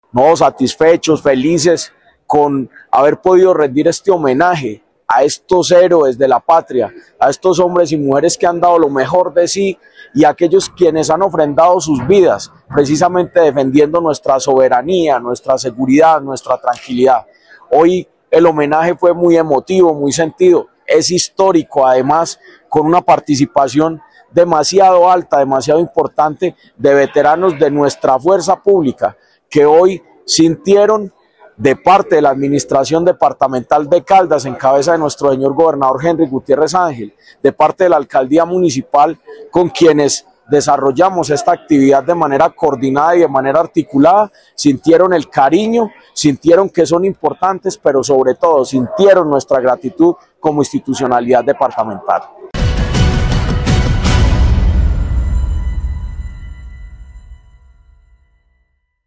En compañía de los comandantes del Ejército y la Policía Nacional de la región, se desarrolló un acto conmemorativo del Día del Veterano de la Fuerza Pública en Caldas.
Secretario de Gobierno de Caldas, Jorge Andrés Gómez Escudero.